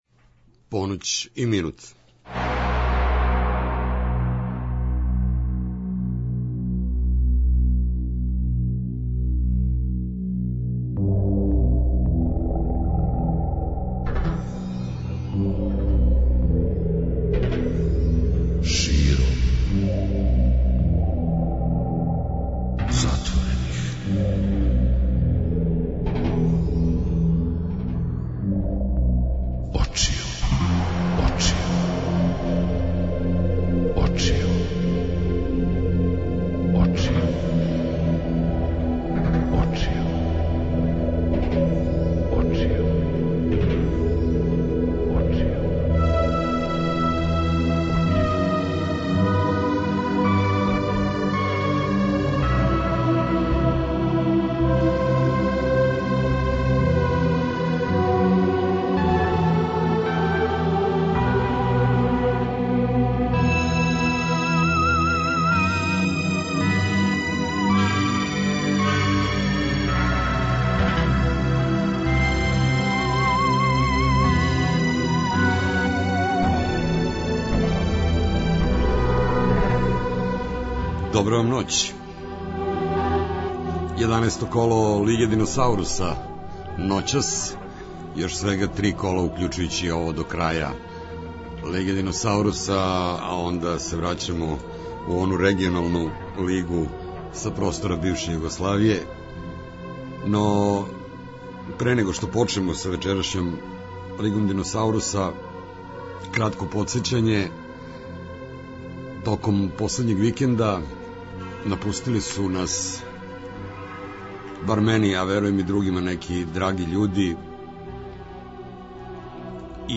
Средом од поноћи - спој добре рок музике, спортског узбуђења и навијачких страсти.